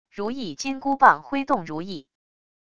如意金箍棒挥动如意wav音频